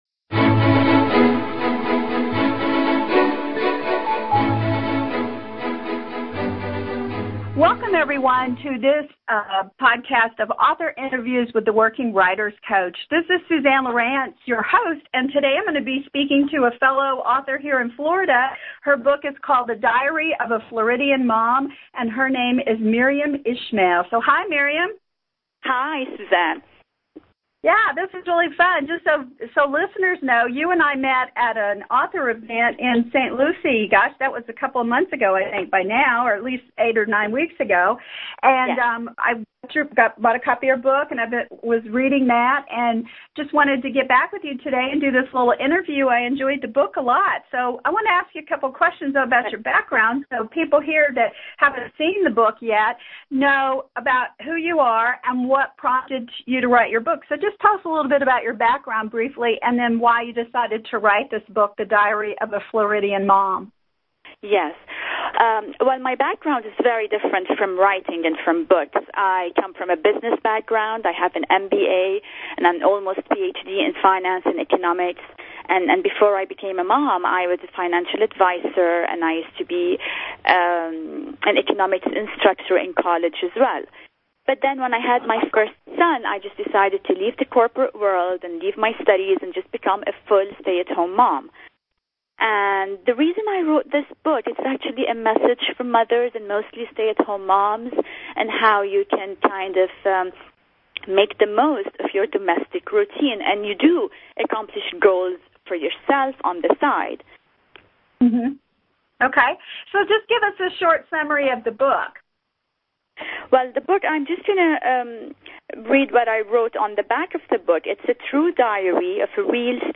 Interview - Law of Attraction for Writers